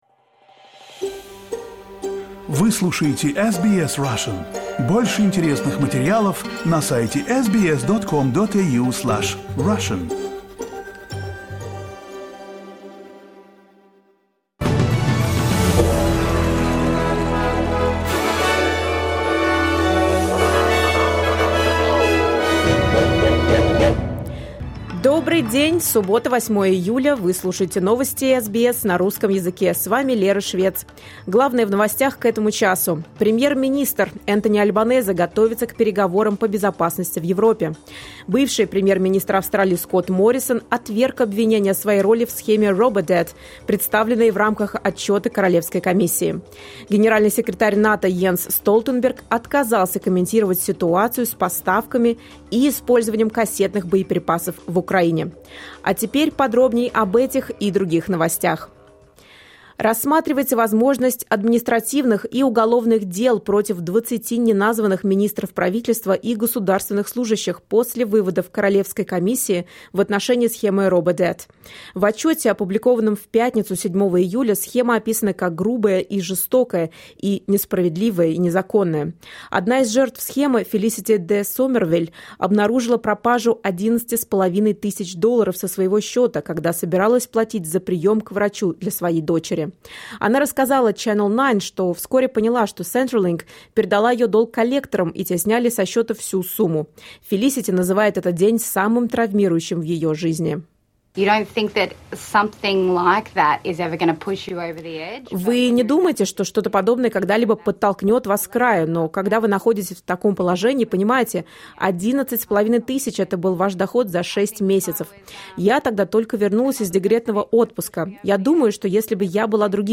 SBS news in Russian — 08.07.2023